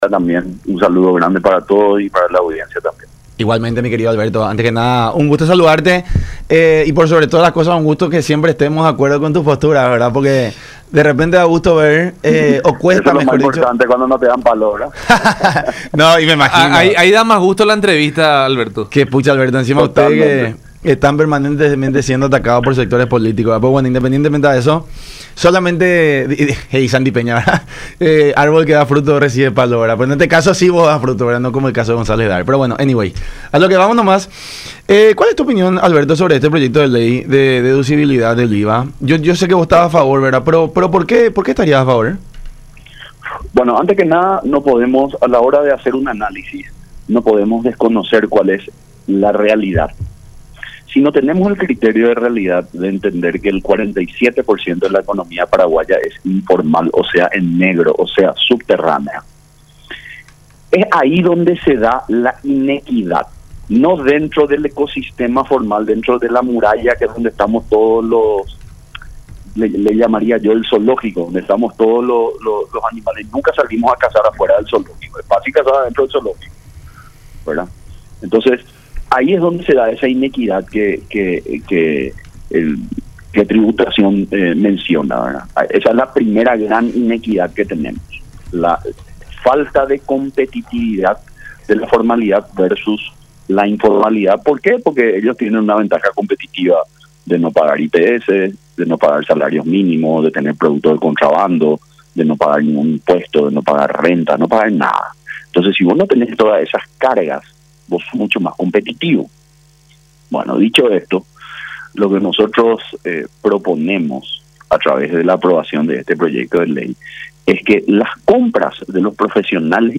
en conversación con La Unión Hace La Fuerza por Unión TV y radio La Unión.